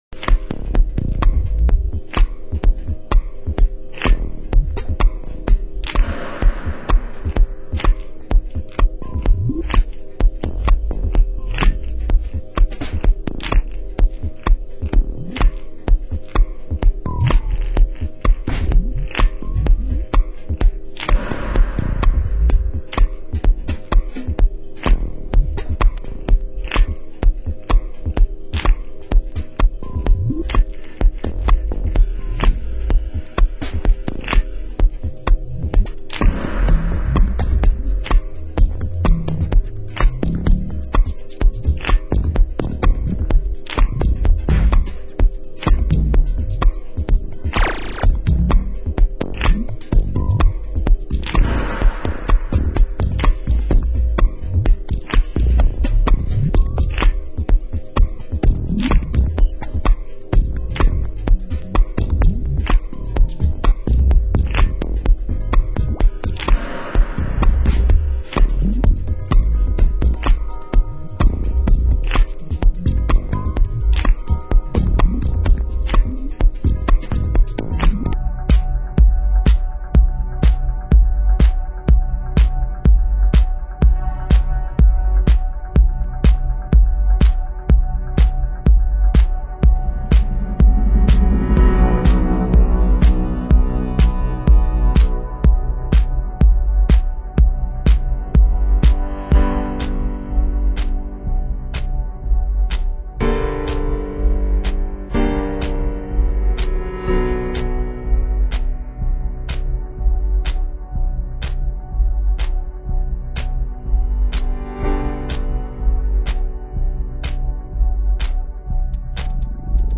Genre: Minimal